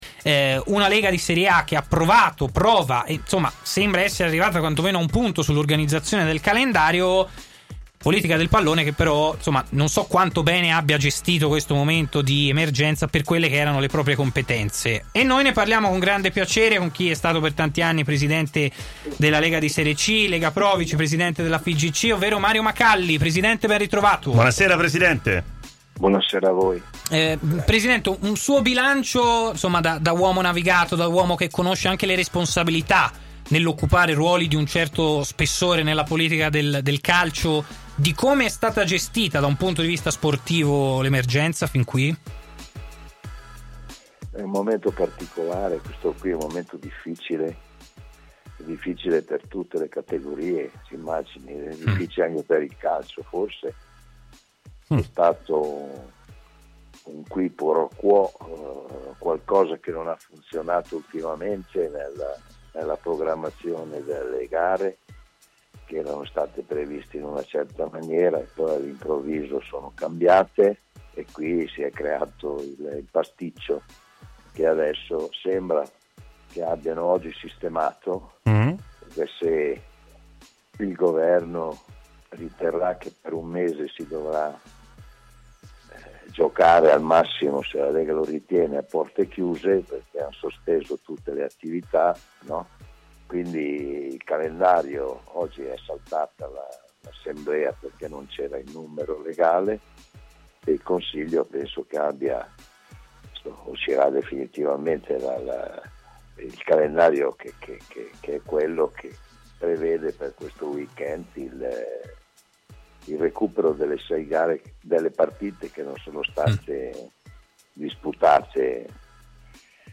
ex presidente della Lega Pro interviene a “Stadio Aperto” su TMW Radio per commentare l’emergenza Coronavirus che sta mettendo in crisi il sistema calcio in Italia.